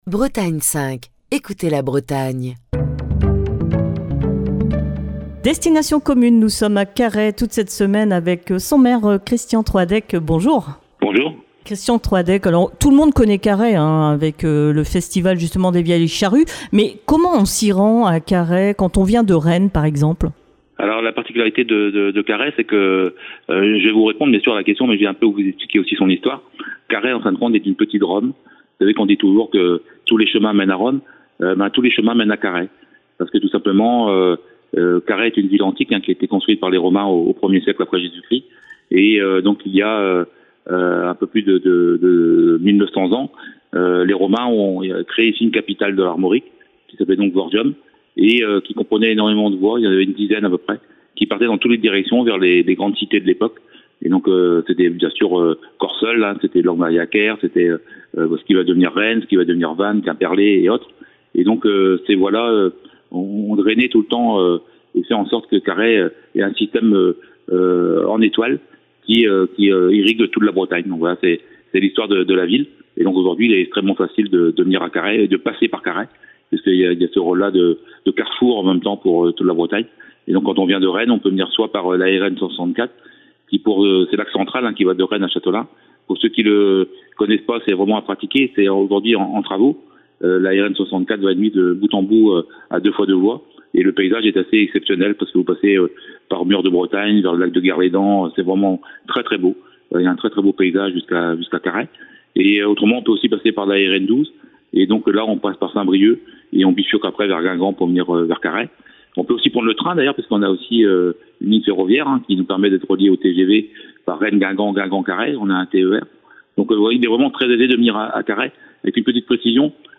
est au téléphone avec Christian Troadec, le maire de Carhaix, qui vous propose de découvrir sa ville et les divers aspects de son quotidien d'élu.